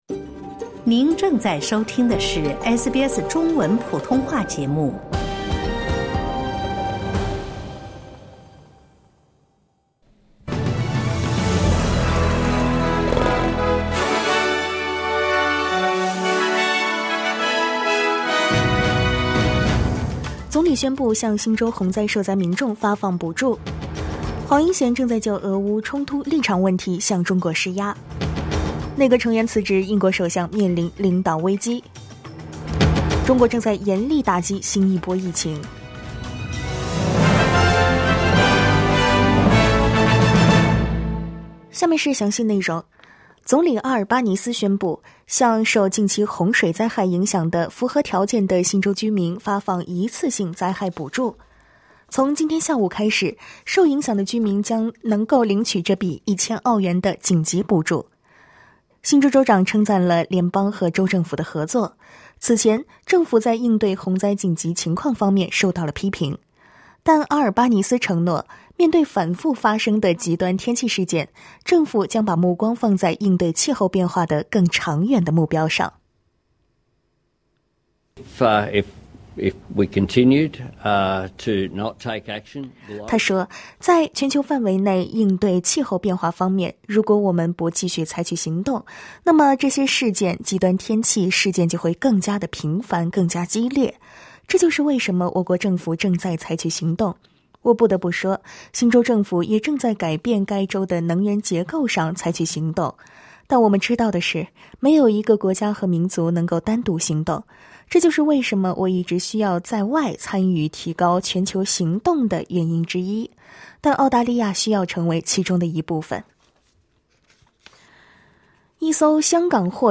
SBS早新闻（7月7日）
请点击收听SBS普通话为您带来的最新新闻内容。